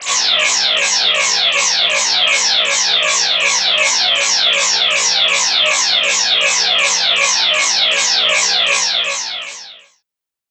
Buzzy Alarm Sound
cartoon